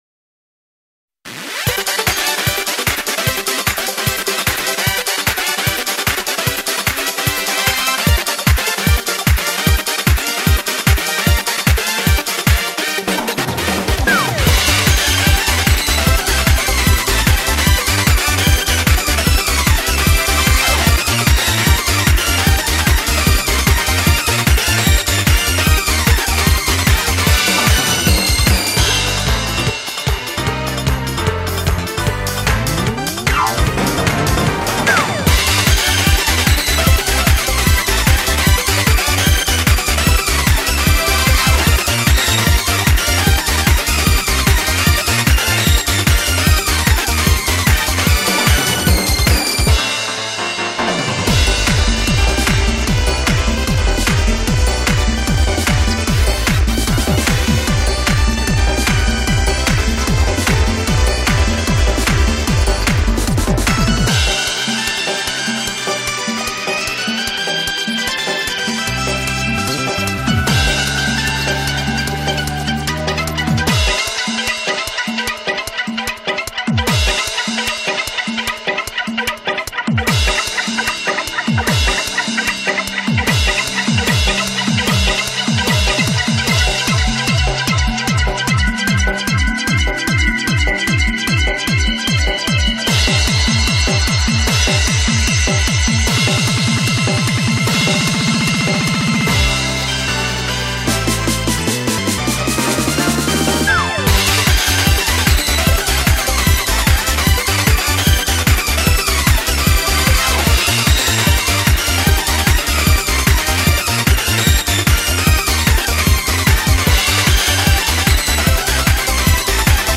BPM150
Audio QualityPerfect (High Quality)